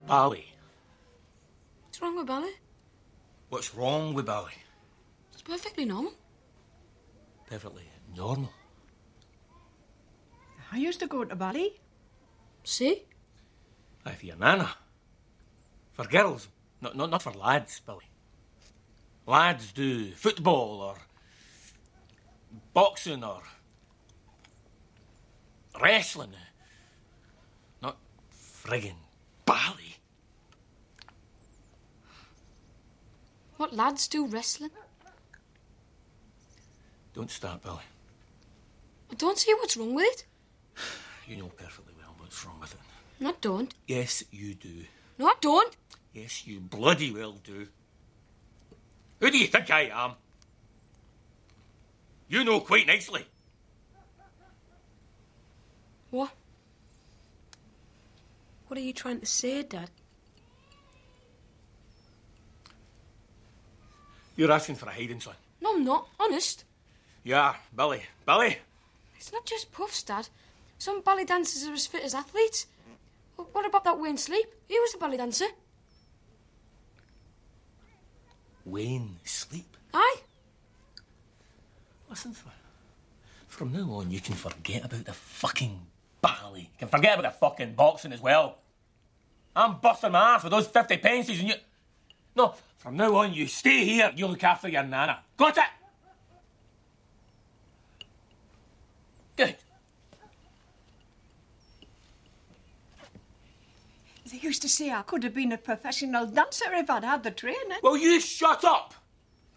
Le père et le fils se disputent : l’un expliquant à l’autre qui ne veut pas l’entendre que la danse n’est pas faite pour les garçons. La dispute est entrecoupée d’une ou deux interventions de la grand-mère.
6.-Dispute-pere-fils-VO.mp3